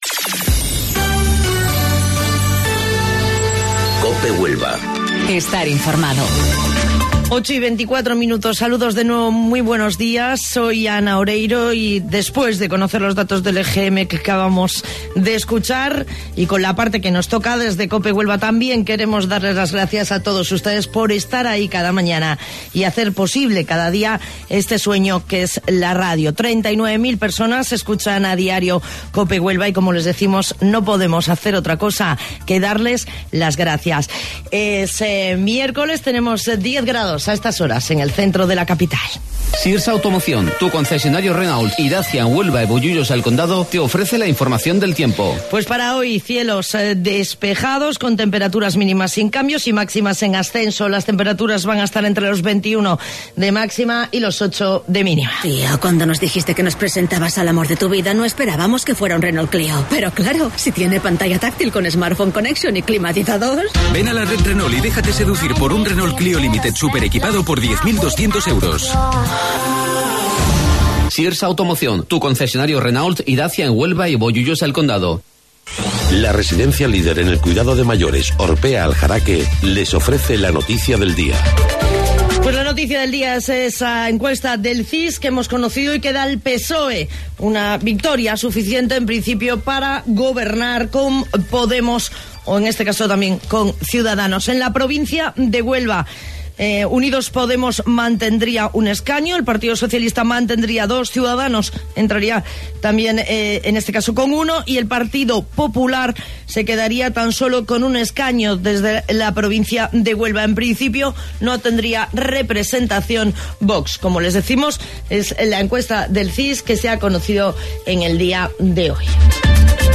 AUDIO: Informativo Local 08:25 del 10 de Abril